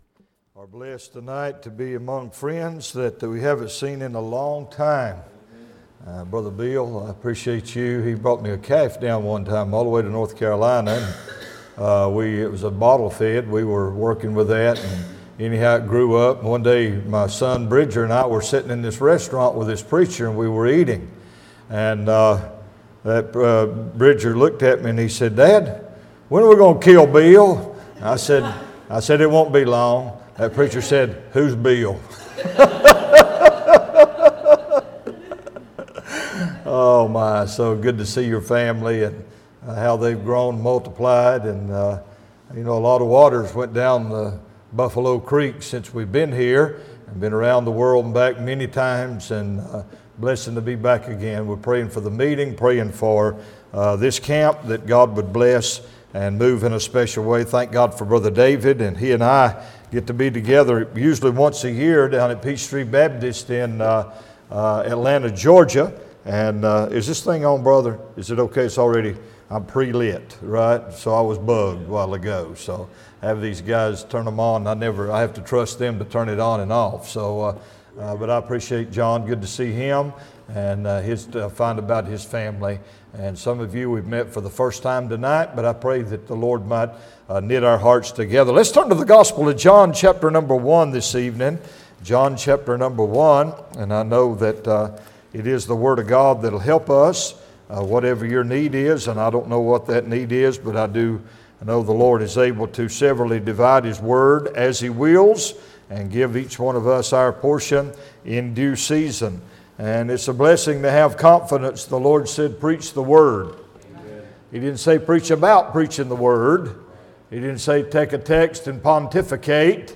Series: 2025 July Conference Passage: John 1 Session: Evening Session